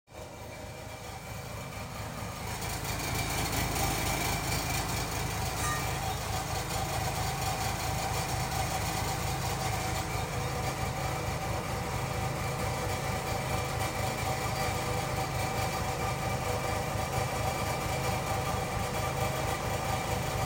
Download Machine sound effect for free.
Machine